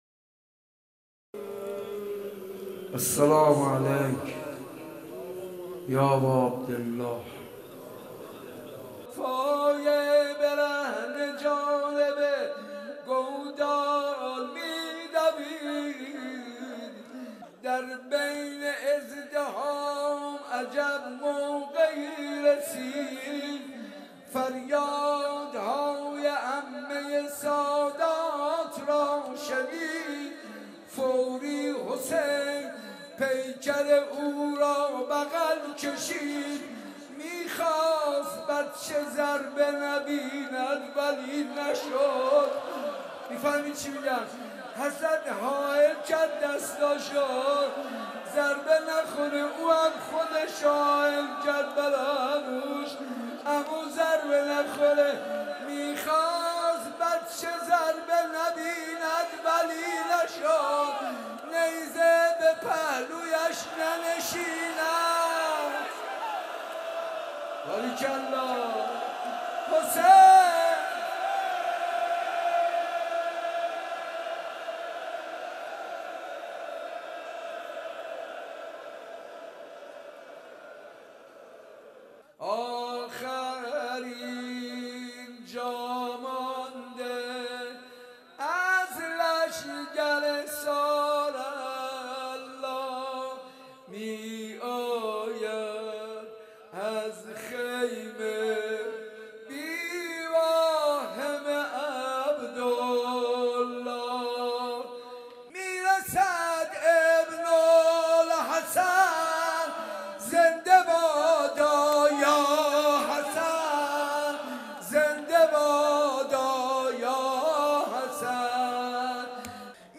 گلچین مداحی ذاکران اهل بیت(ع) در شب پنجم محرم